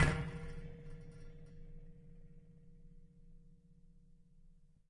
乐器是由金属弹簧从一个大的卡拉巴什壳上延伸出来的；录音是用一对土工话筒和一些KK;接触话筒录制的，混合成立体声。 动态用pp（软）到ff（大）表示；名称表示记录的动作。
Tag: 声学的 金属制品 冲击 弹簧 拉伸